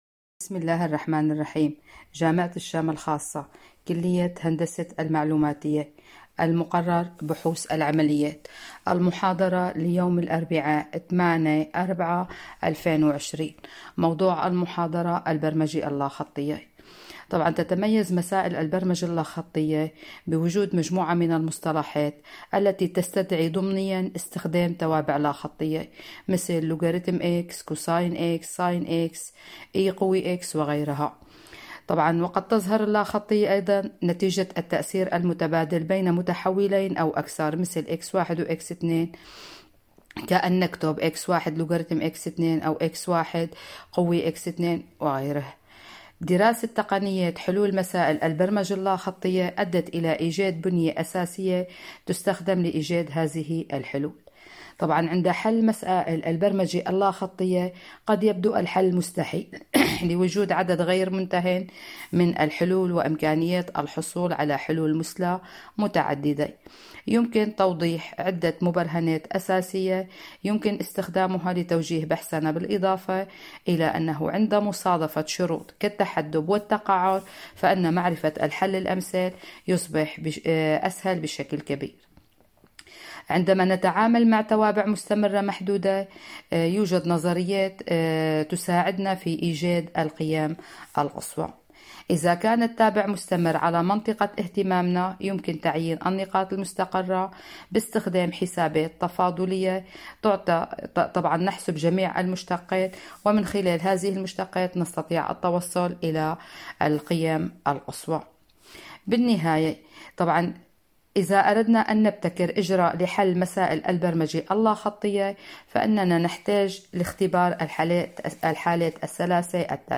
محاضرة بحوث العمليات _ البرمجة اللاخطية